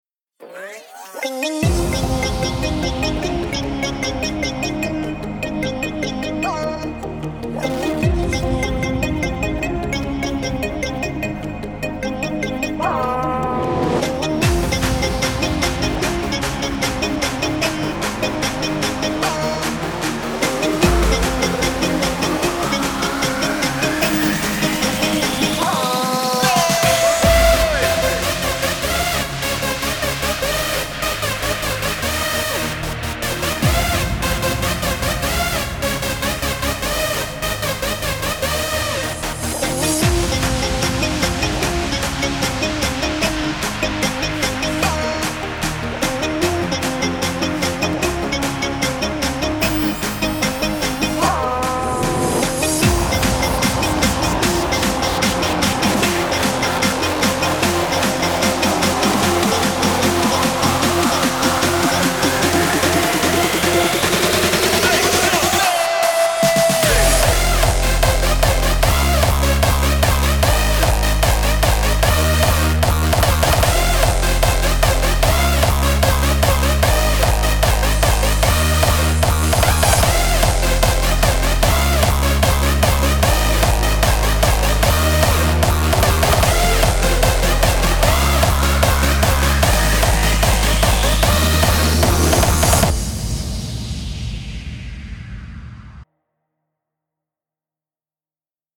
5天前 DJ音乐工程 · Hardstyie风格 3 推广